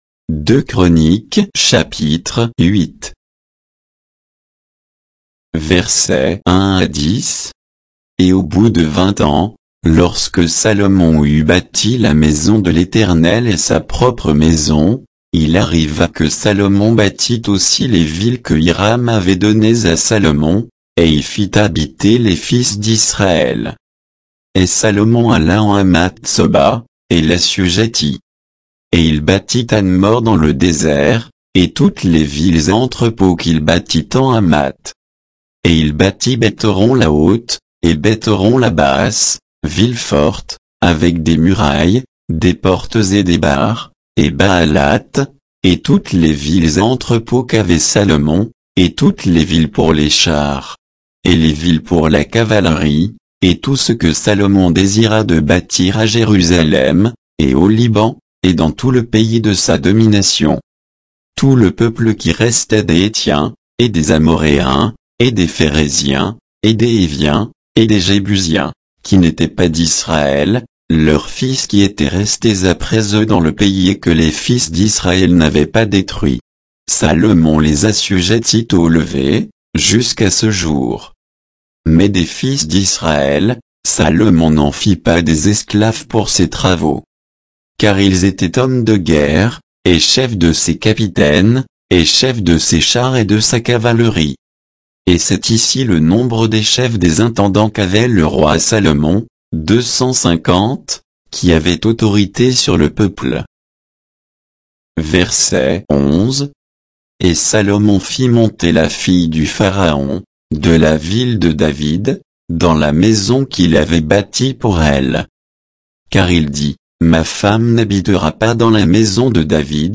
Bible_2_Chroniques_8_(sans_notes,_avec_indications_de_versets).mp3